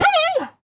sounds / mob / panda / hurt3.ogg
hurt3.ogg